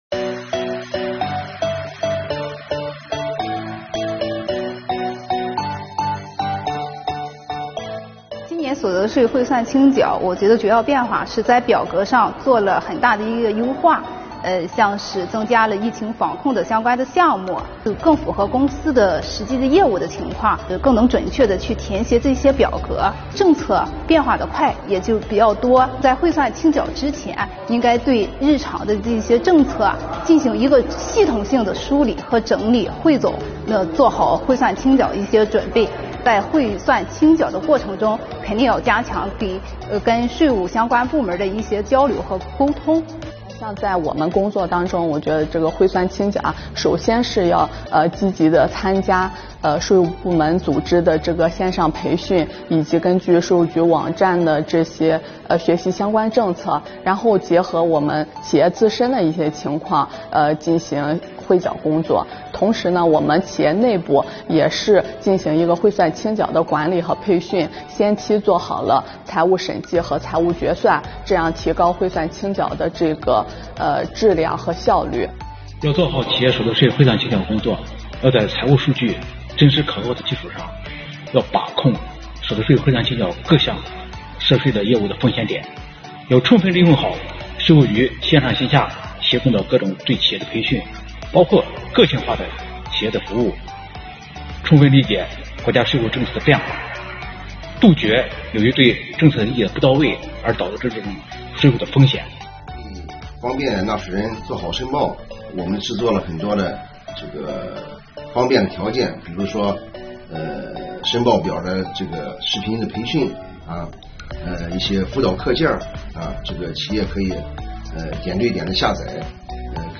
点击下方视频，听听纳税人具体怎么说~~